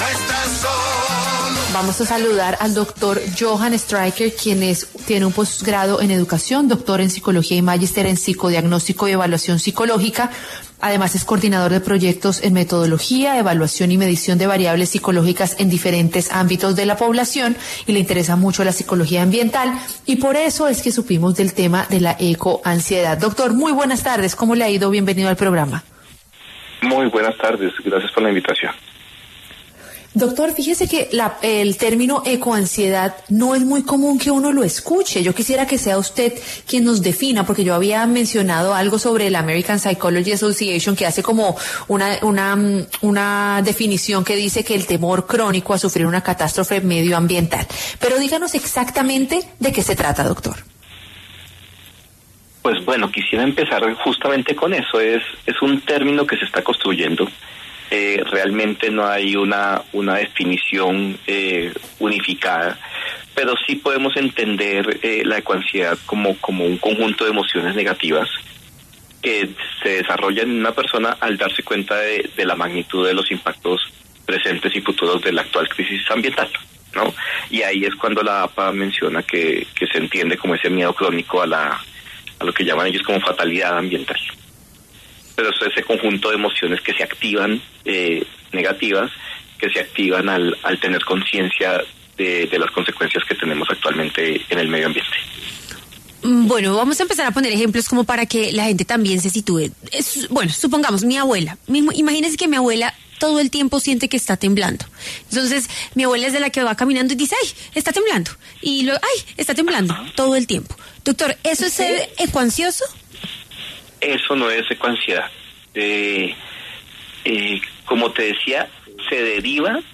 Psicólogo habló de este padecimiento